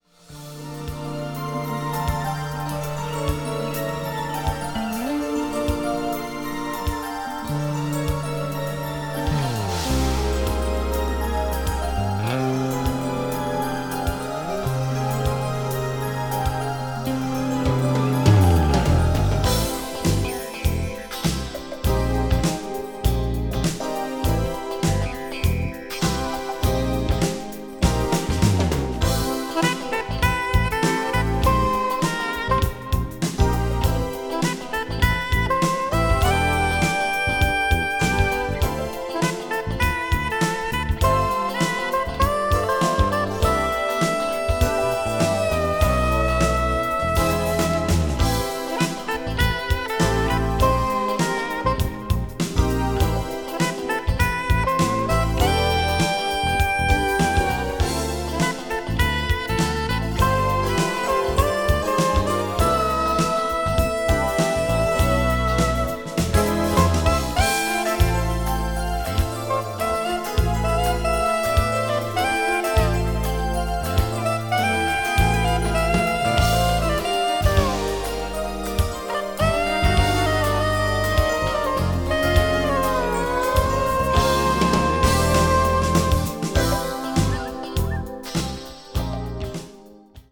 media : EX/EX(some slightly noises.)
crossover   fusion   jazz groove